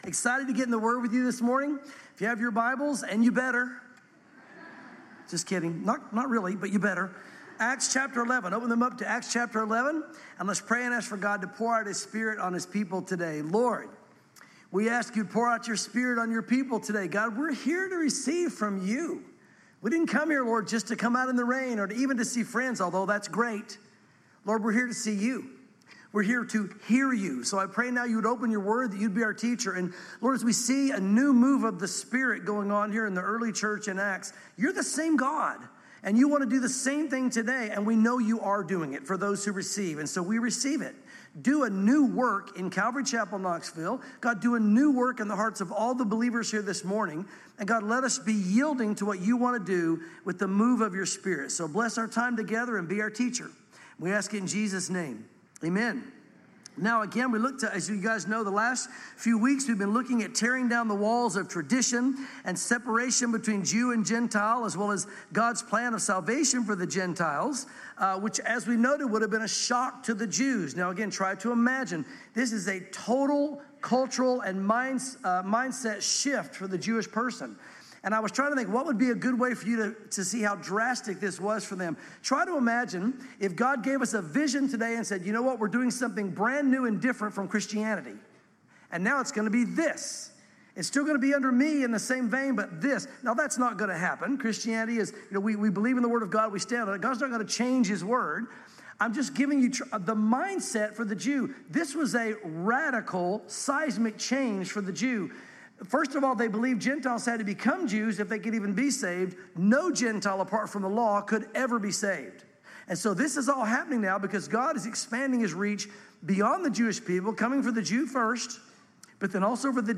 sermons Acts 11 | A New Move of the Spirit